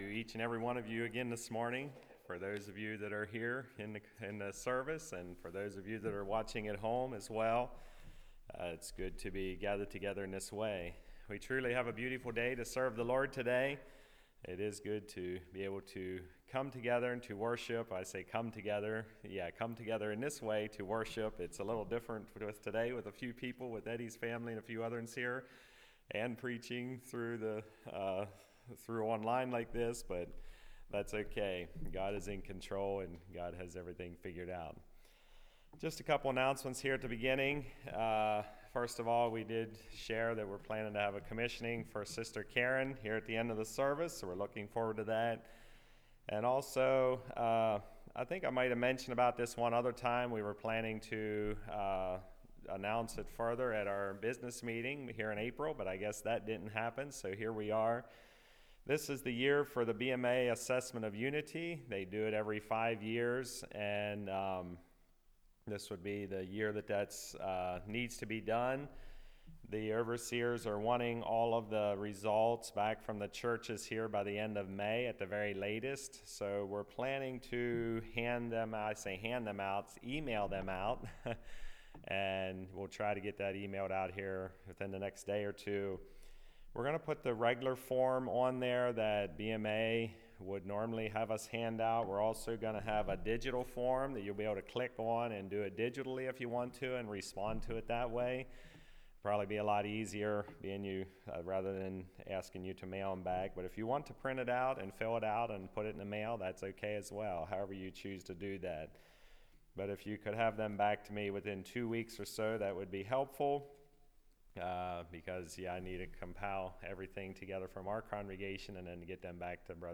Ephesians 6:10-18 Service Type: Message Topics: praying , spiritual armor « A Celebration of Life